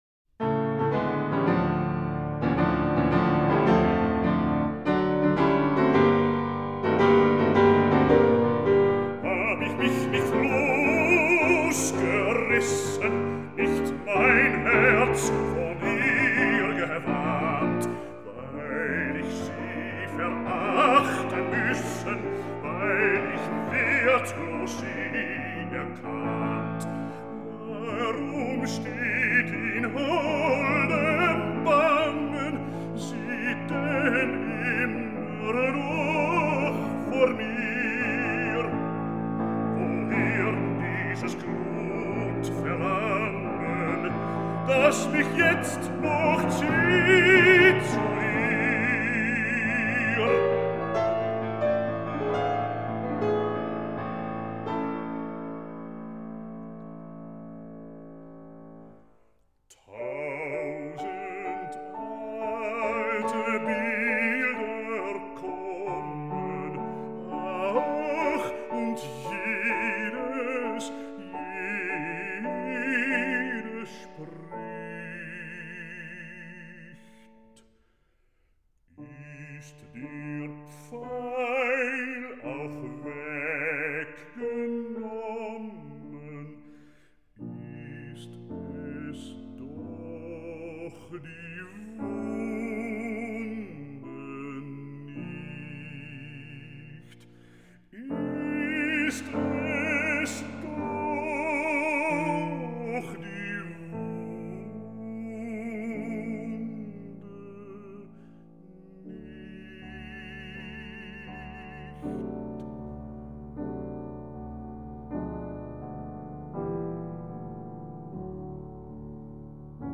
Robert Holl - Bariton,
Piano